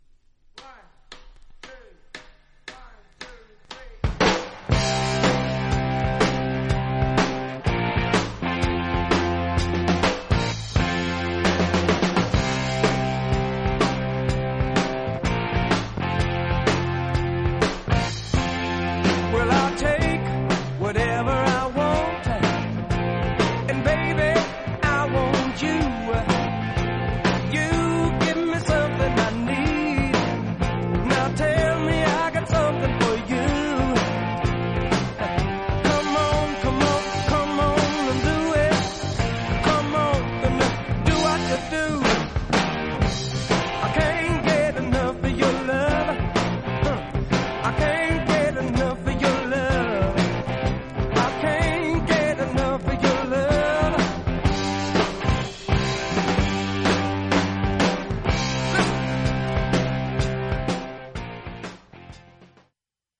盤面も薄いスリキズがそこそこありますが音への影響は少ないと思います。
実際のレコードからのサンプル↓ 試聴はこちら： サンプル≪mp3≫